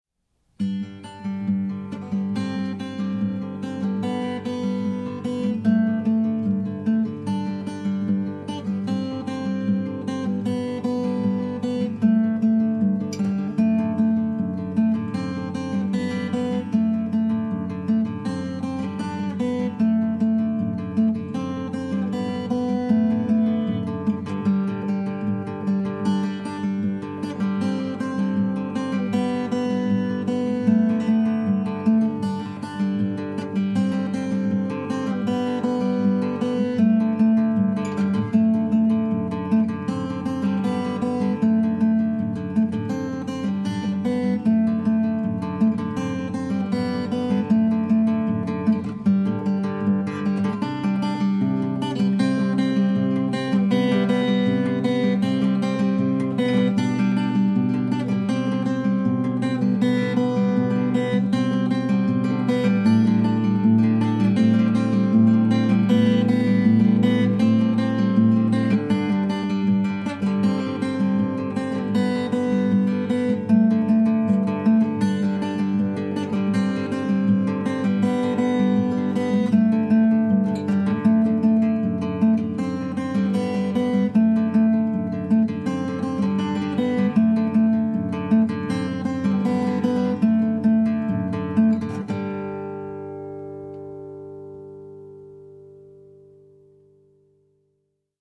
mono
(instrumental) All music